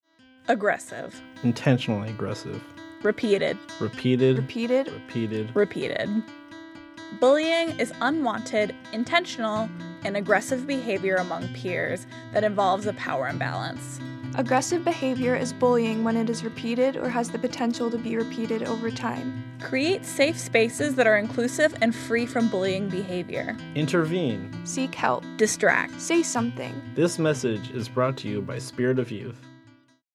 KNBA youth health reporters created these two public service announcements about bullying behavior.